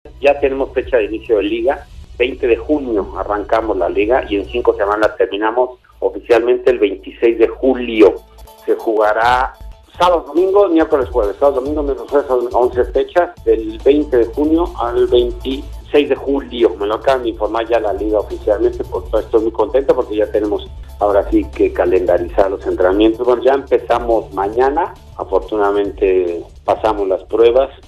(Javier Aguirre, DT del Leganés, en entrevista con Marca)